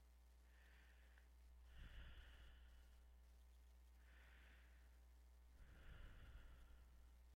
吸气
描述：通过鼻子深呼吸。
标签： 鼻子 呼吸
声道立体声